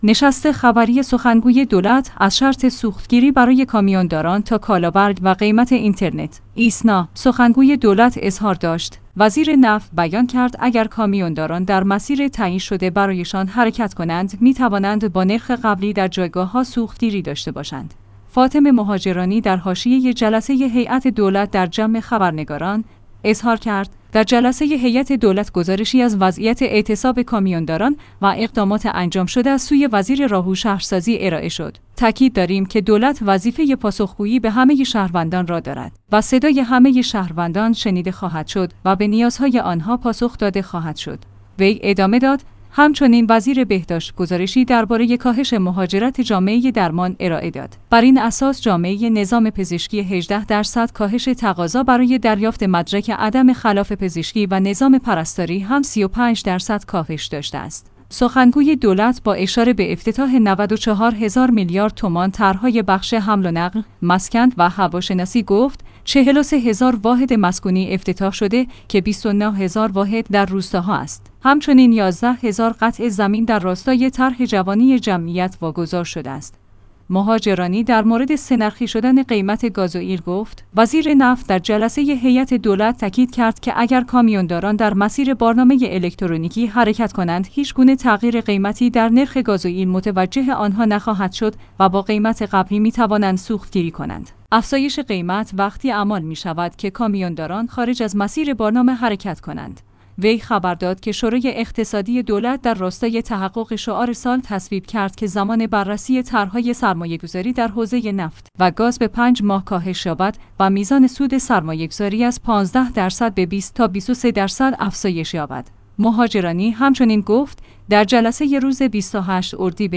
نشست خبری سخنگوی دولت؛ از شرط سوختگیری برای کامیون‌داران تا کالابرگ و قیمت اینترنت
فاطمه مهاجرانی در حاشیه جلسه هیات دولت در جمع خبرنگاران، اظهار کرد: در جلسه هیات دولت گزارشی از وضعیت اعتصاب کامیون‌داران و اقدامات انجام شده ا